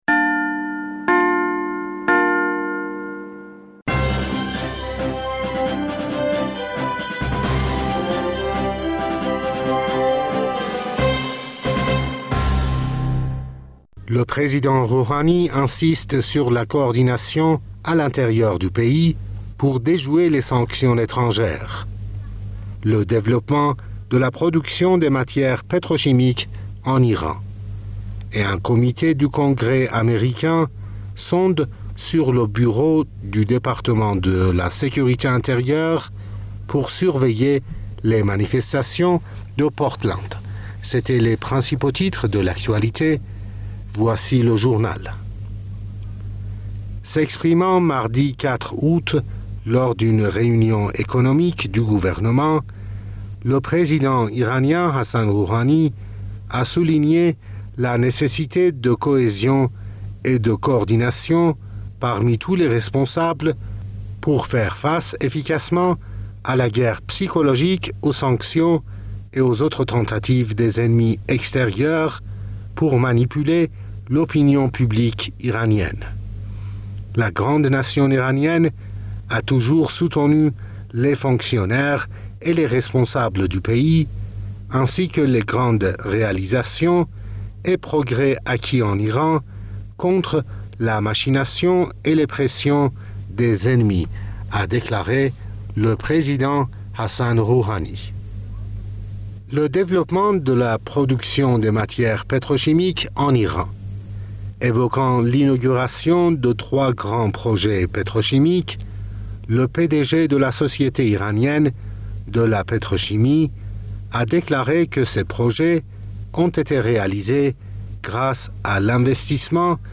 Bulletin d'information du 04 Aout 2020